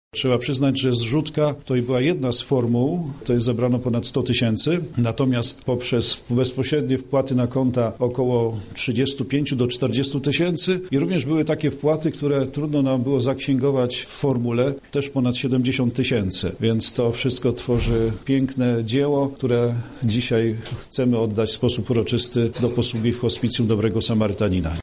Za pomocą internetowej zbiórki pod hasłem „Jazda dla hospicjum” udało się uzbierać środki na zakup ambulansu dla podopiecznych placówki. Zebraliśmy więcej niż planowaliśmy – mówi jej prezes, a także rektor KUL, ks. prof. Mirosław Kalinowski: